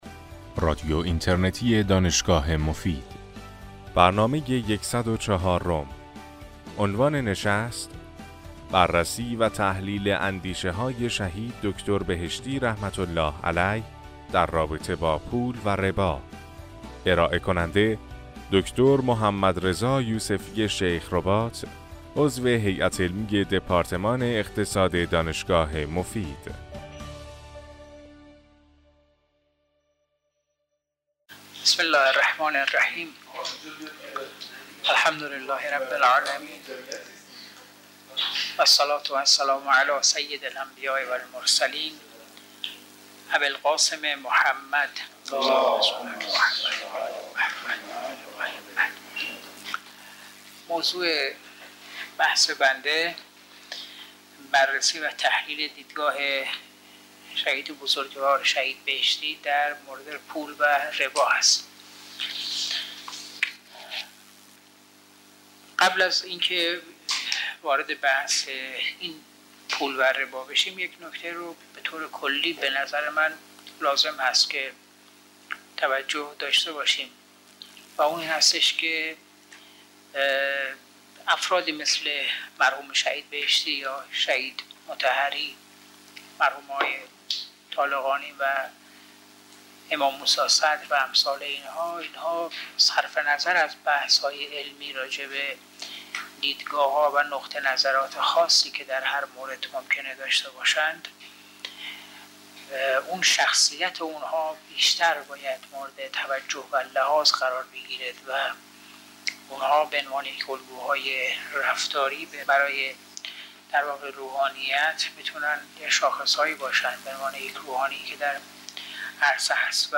در این سخنرانی که در سال 1392 ایراد شده